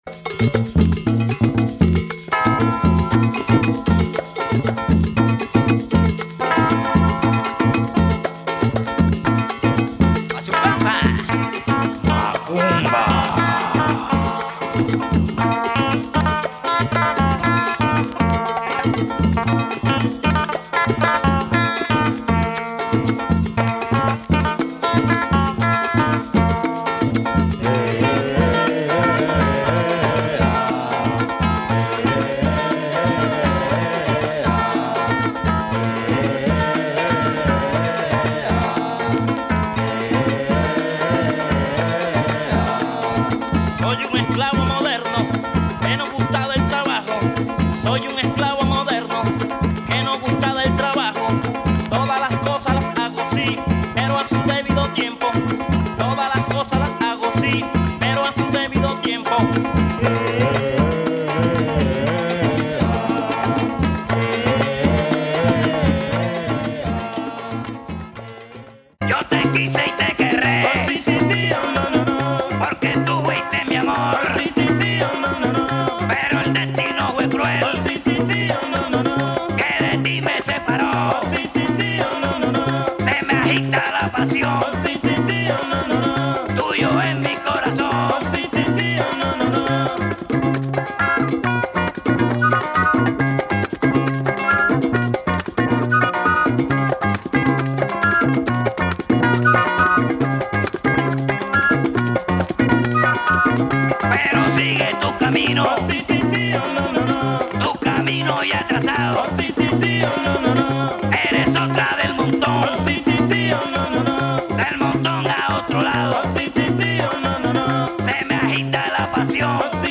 Marvelous groovy champeta